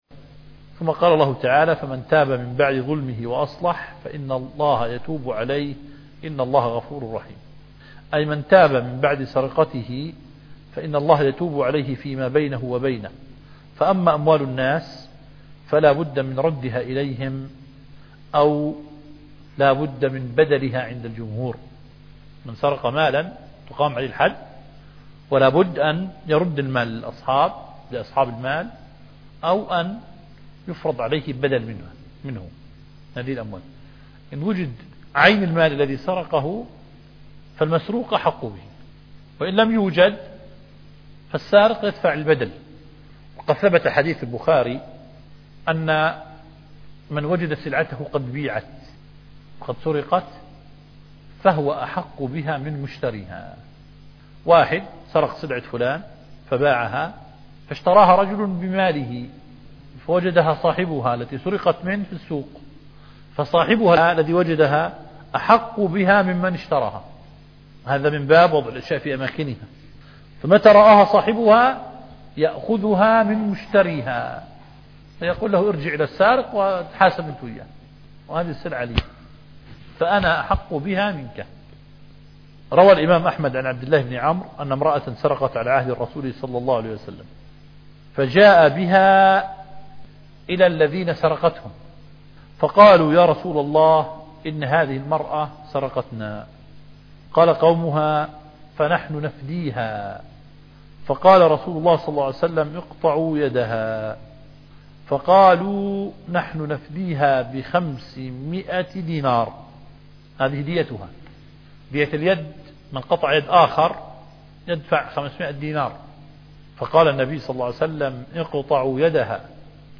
التفسير